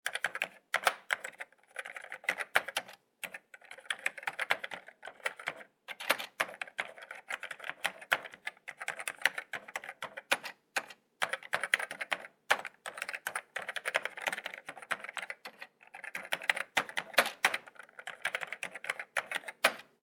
Typing-sound-effect-keyboard.wav